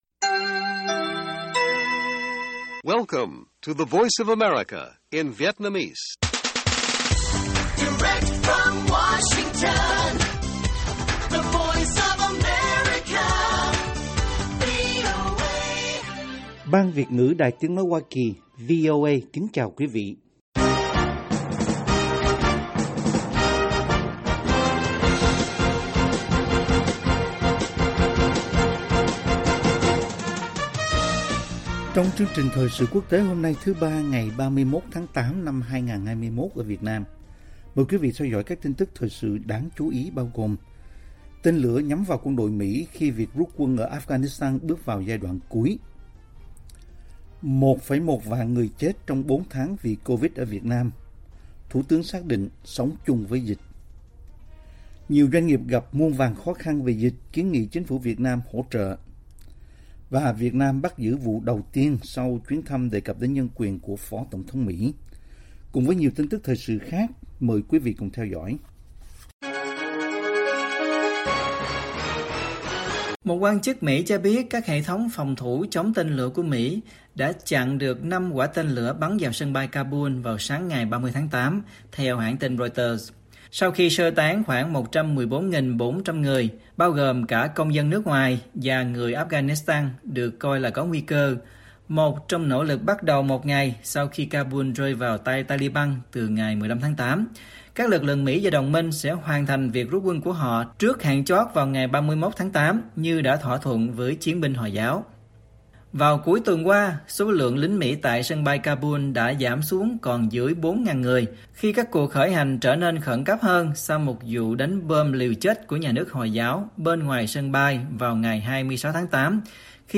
Bản tin VOA ngày 31/8/2021